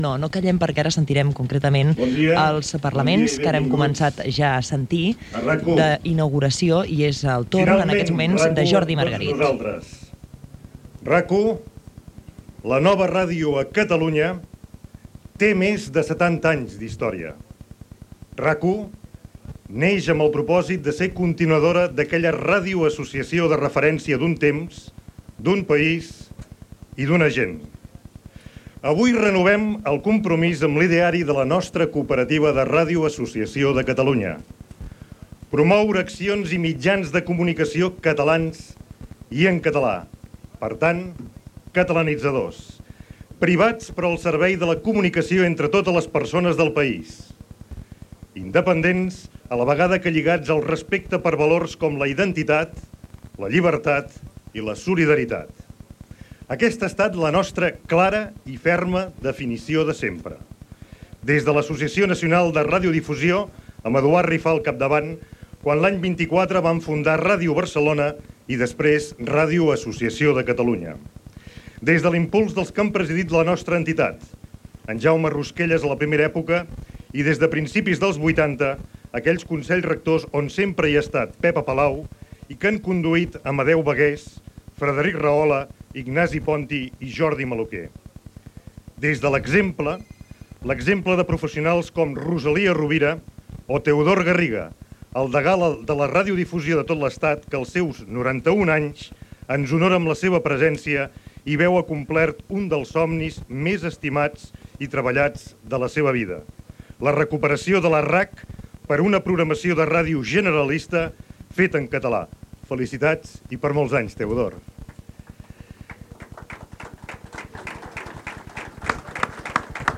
Informatiu
Acte inaugural fet el matí del segon dia d'emissió.